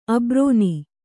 ♪ abrōni